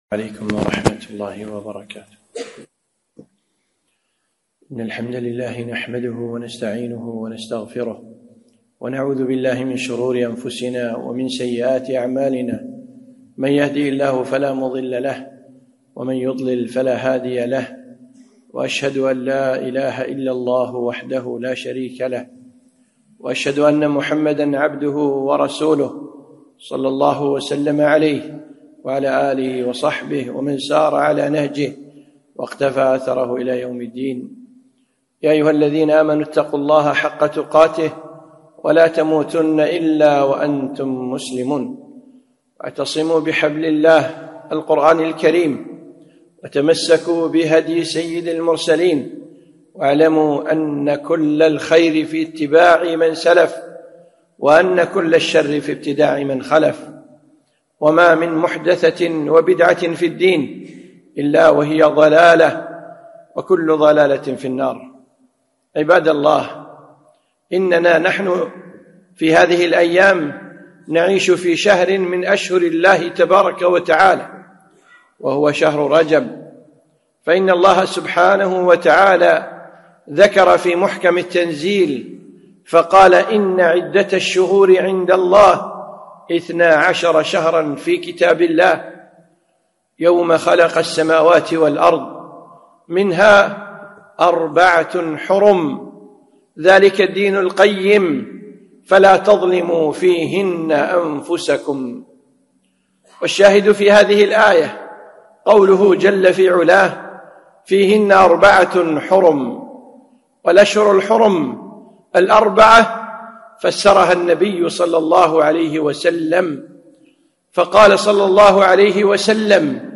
خطبة - رجب الحرام